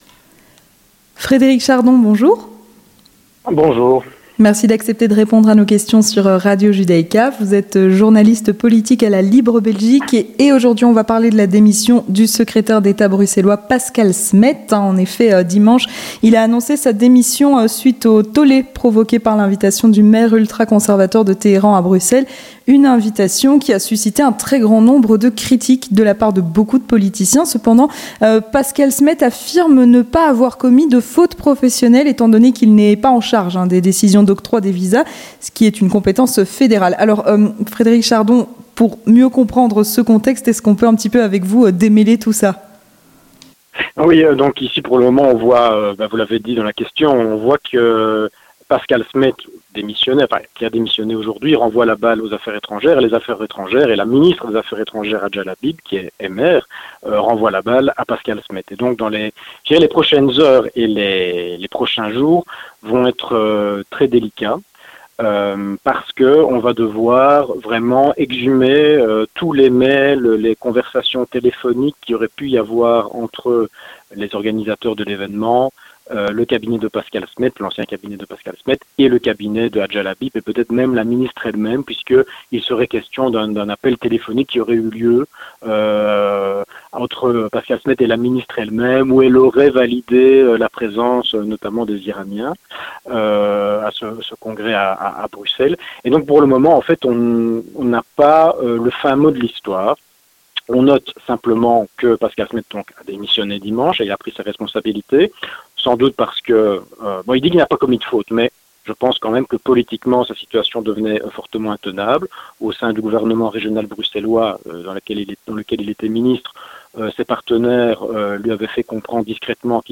Entretien du 18h - La démission de Pascal Smet